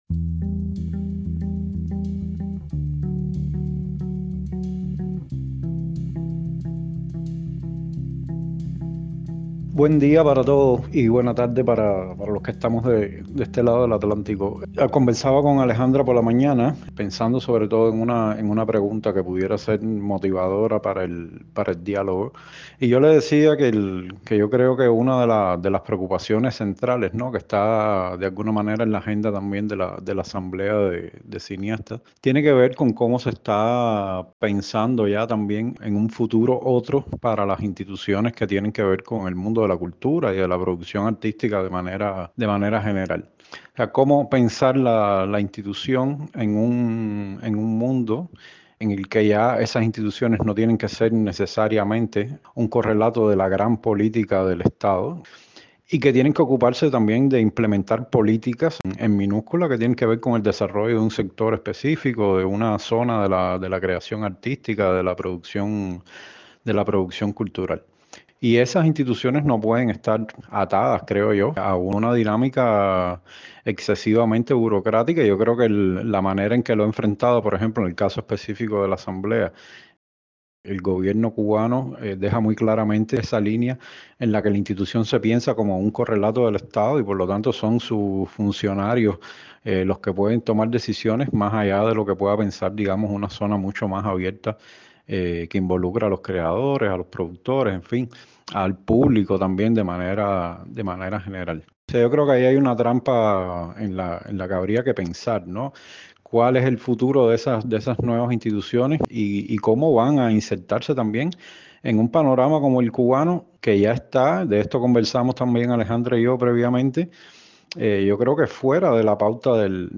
Para hablar de la Asamblea de Cineastas Cubanos es pertinente una conversación que incluya varias voces. La pregunta es ¿por qué razón el gobierno no quiere aceptar una de nuestras propuestas?